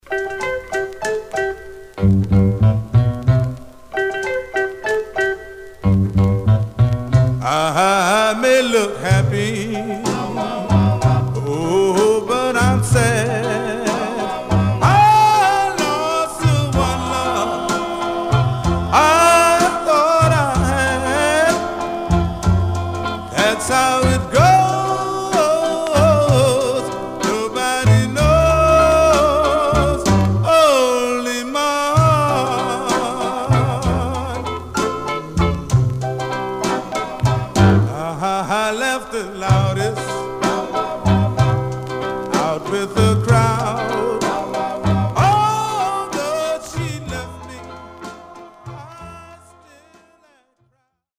Mono
Male Black Group Condition